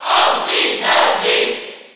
Category:Crowd cheers (SSB4) You cannot overwrite this file.
Greninja_Cheer_French_PAL_SSB4.ogg